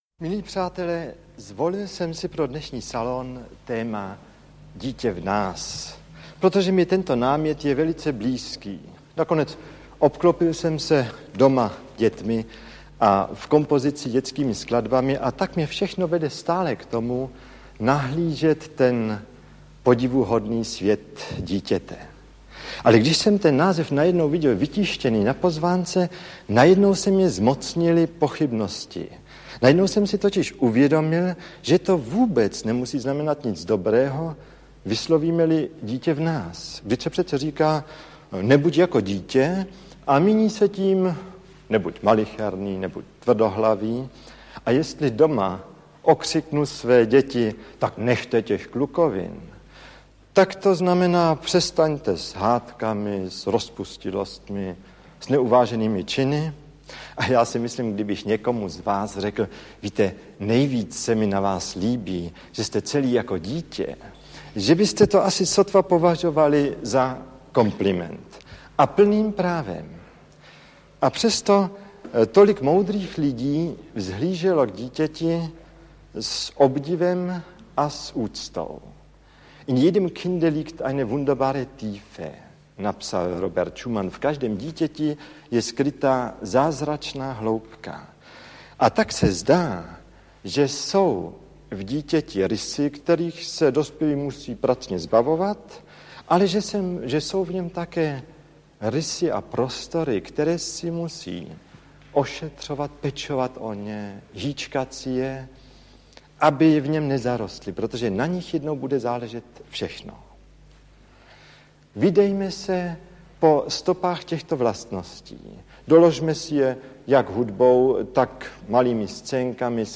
Dítě v nás audiokniha
Zamyšlení hostitele a průvodce večerem Petra Ebena na téma "Dítě v nás"